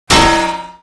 acannonimpactmetala04.wav